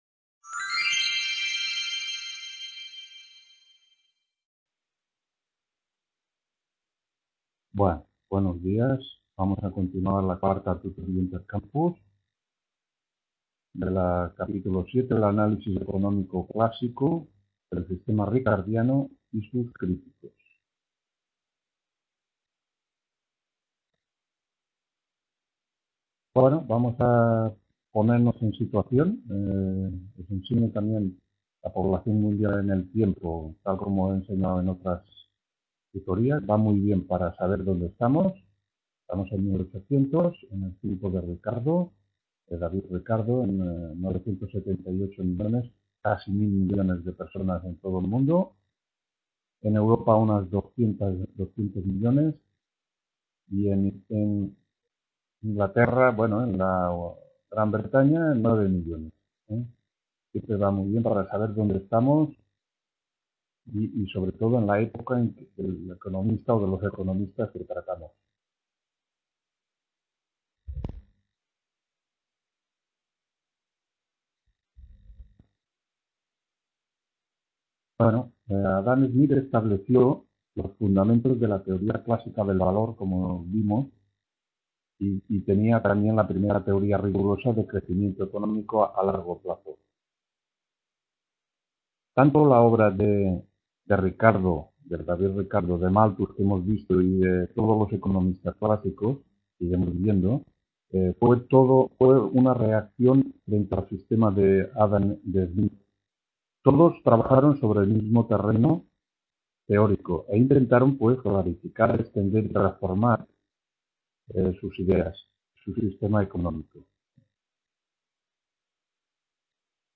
4ª TUTORÍA INTERCAMPUS HISTORIA DEL PENSAMIENTO ECONÓMICO (nº 27)